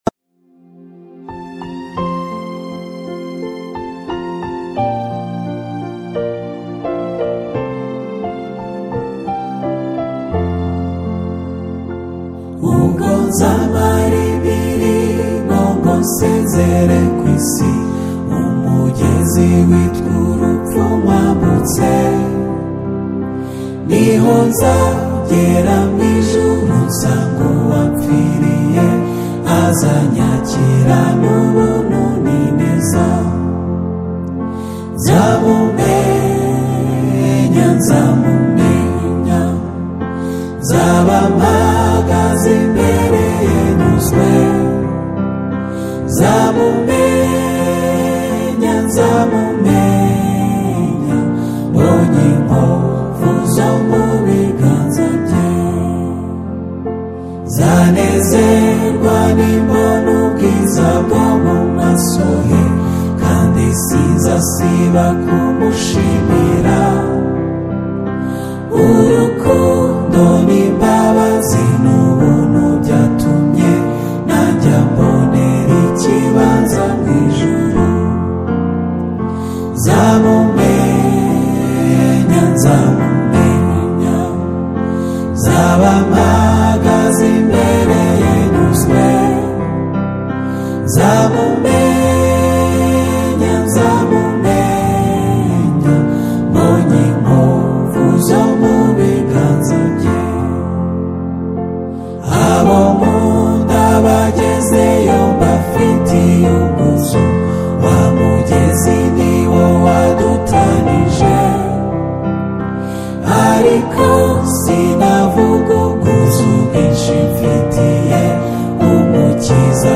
a heart-touching acoustic worship ballad